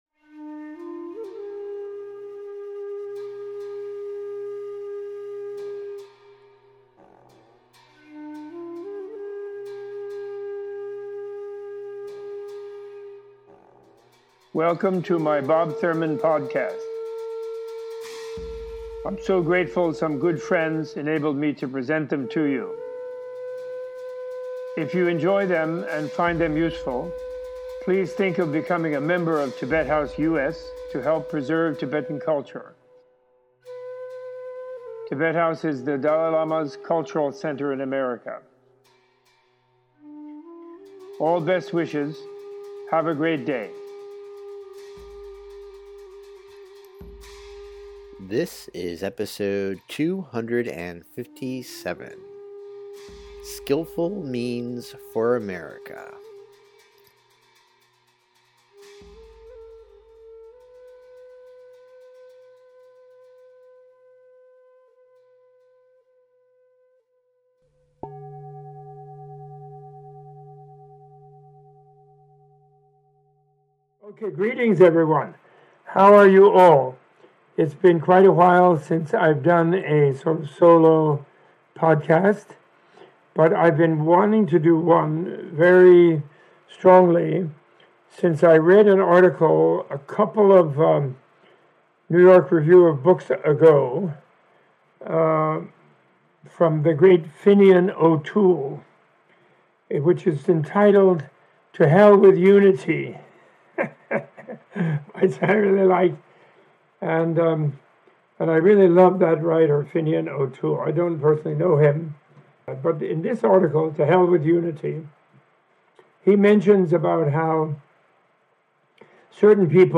Using the writings of Fintan O’Toole, Michelle Alexander and Heather Cox Richardson, Robert A.F. Thurman gives a teaching on the roots of the political, economic and environmental crises being faced by Americans and those in the developed modern world.